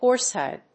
音節hórse・hìde